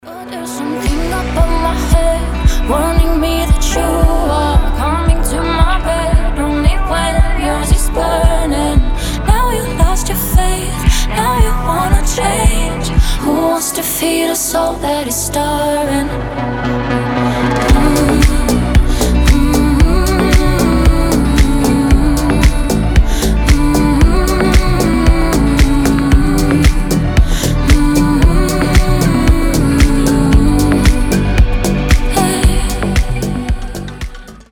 • Качество: 320, Stereo
гитара
deep house
красивый женский голос